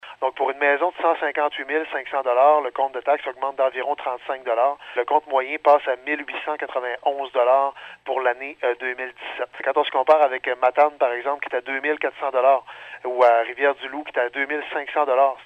Le maire de Gaspé, Daniel Côté, affirme que cela va se traduire par une hausse moyenne du compte de taxes de 35 $ pour un contribuable qui possède une résidence évaluée à 158 500 $.